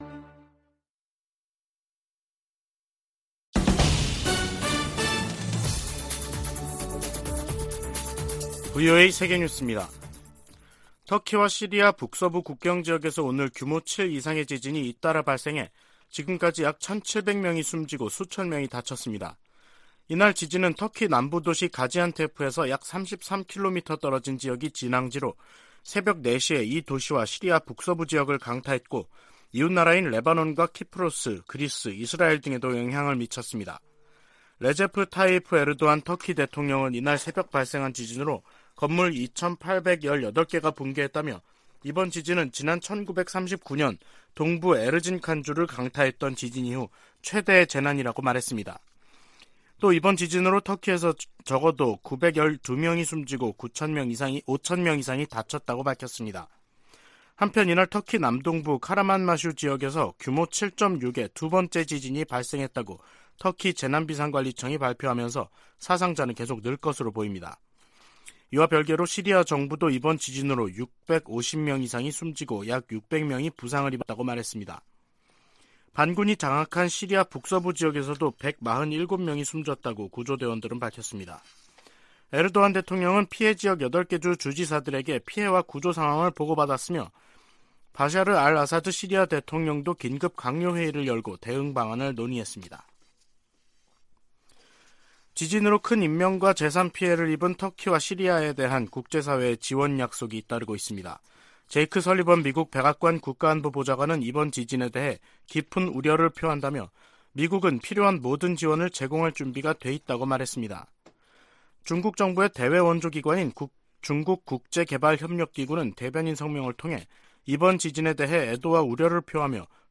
VOA 한국어 간판 뉴스 프로그램 '뉴스 투데이', 2023년 2월 6일 3부 방송입니다. 워싱턴에서 열린 미한 외교장관 회담에서 토니 블링컨 미 국무장관은, ‘미국은 모든 역량을 동원해 한국 방어에 전념하고 있다’고 말했습니다. 미국과 중국의 ‘정찰 풍선’ 문제로 대립 격화 가능성이 제기되고 있는 가운데, 북한 문제에 두 나라의 협력 모색이 힘들어질 것으로 전문가들이 내다보고 있습니다.